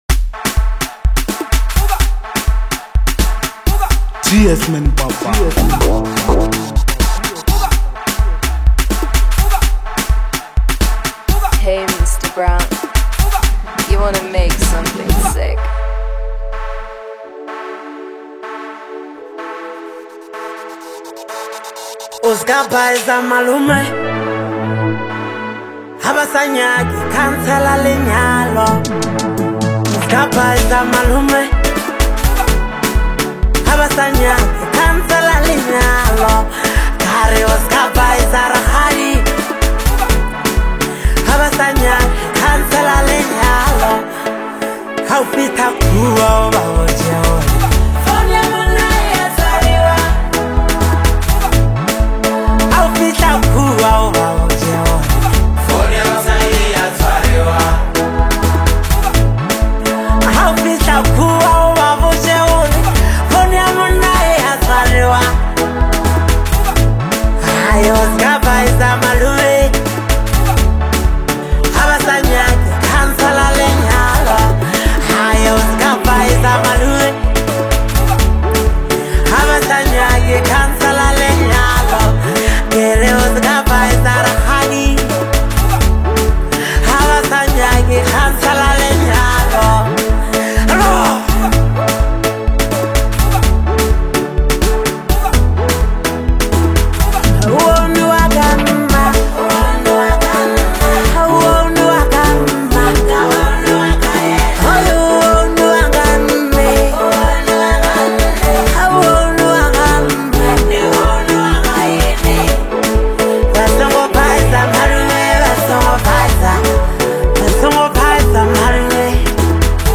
DANCE Apr 07, 2026